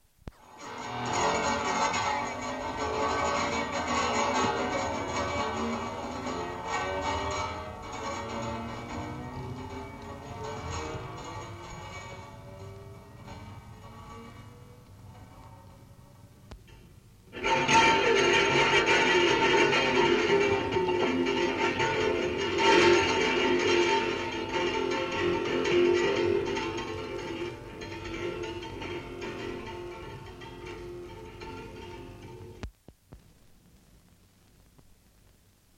杂项复古光学音效 " G3904除夕之夜
描述：新年前夜的庆祝活动。漫画各种各样的铃铛，喇叭，欢呼声，喧闹声。版本1。 这些是20世纪30年代和20世纪30年代原始硝酸盐光学好莱坞声音效果的高质量副本。 40年代，在20世纪70年代早期转移到全轨磁带。我已将它们数字化以便保存，但它们尚未恢复并且有一些噪音。
Tag: 庆典 眼镜 复古 环境